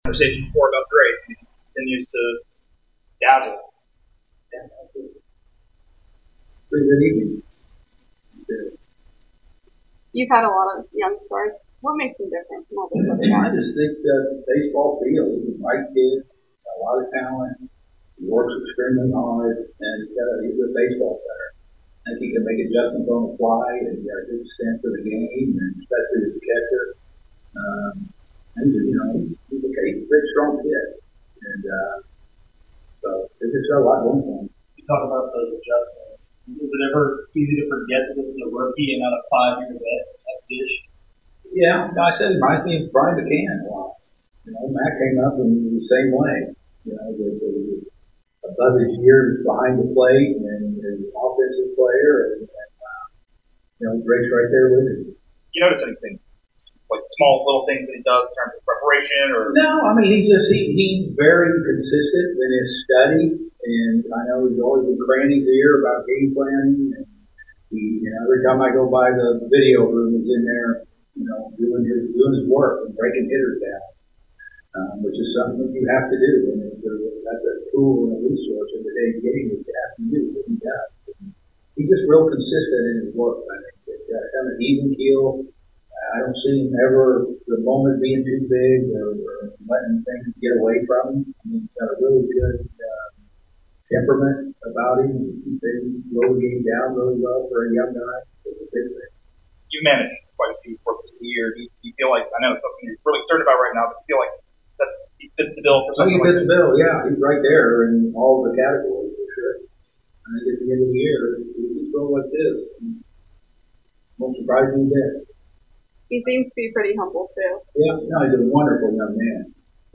Atlanta Braves Manager Brian Snitker Postgame Interview after defeating the Miami Marlins at Truist Park.